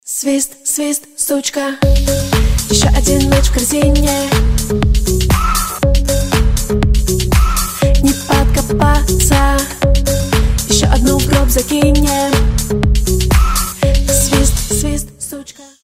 • Качество: 320, Stereo
женский вокал